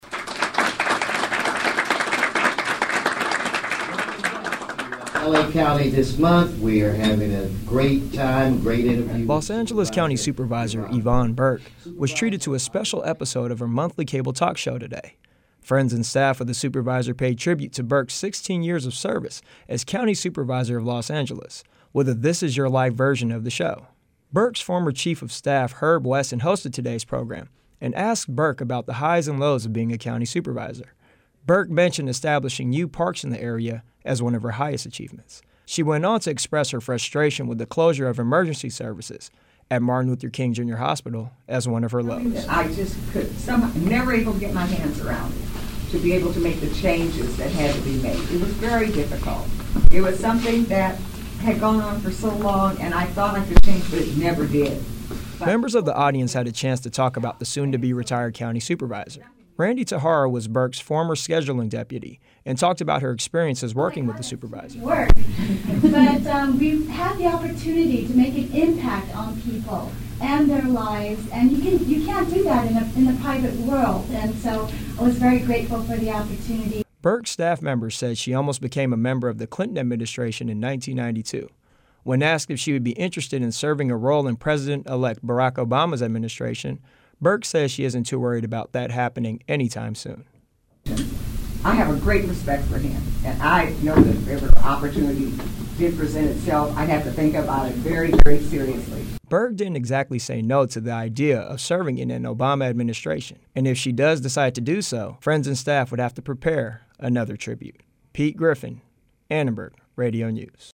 Friends and staff of LA County Supervisor Yvonne Burke paid tribute to her at the supervisor’s monthly cable talk show in Culver City, California.